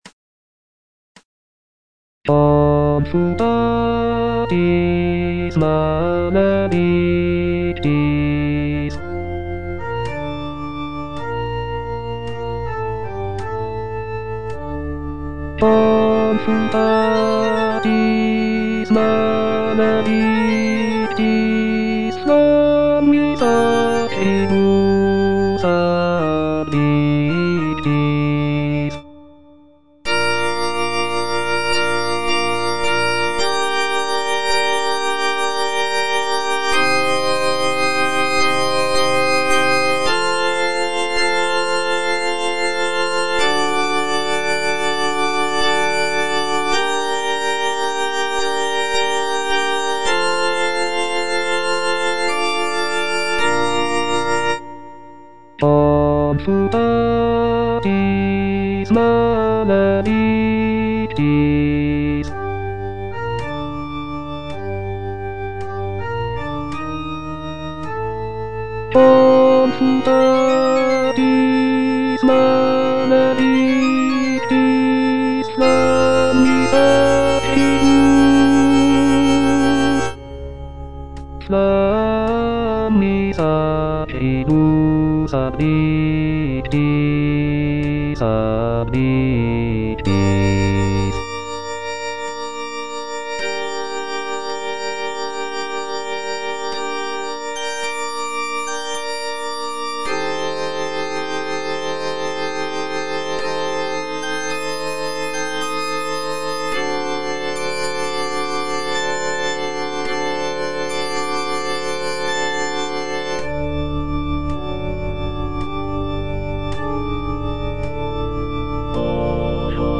F. VON SUPPÈ - MISSA PRO DEFUNCTIS/REQUIEM Confutatis (bass I) (Voice with metronome) Ads stop: auto-stop